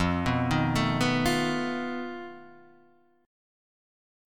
F 7th Flat 5th